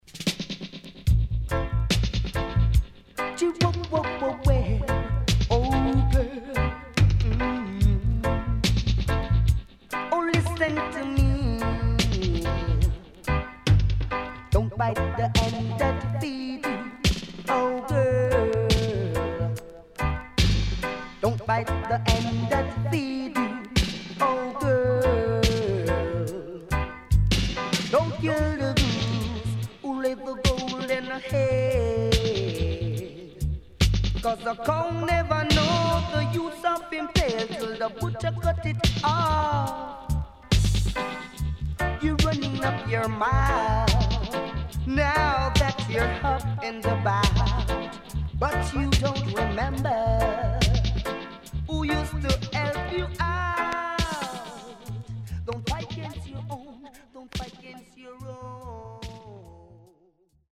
HOME > Back Order [DANCEHALL LP]
83年Recorded & Mixed at CHANNEL ONE
SIDE A:少しノイズ入りますが良好です。